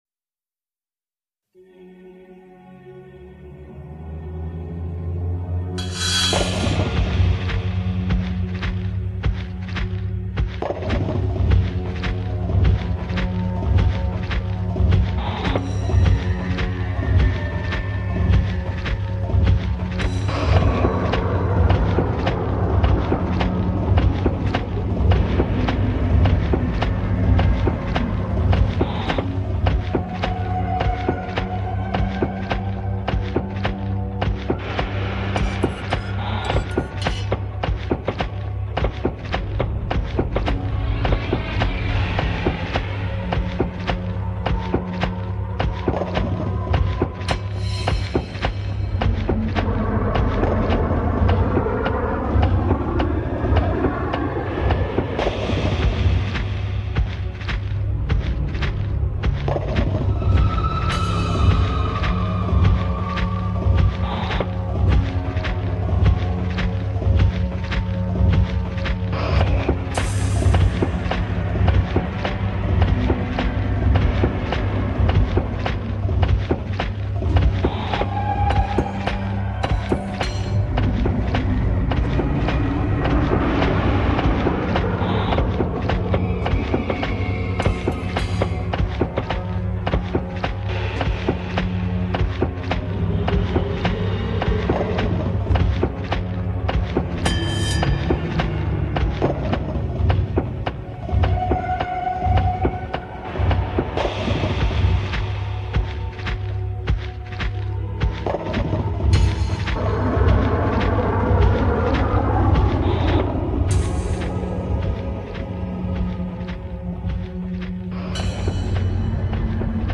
ambient industrial, trip hop, downtempo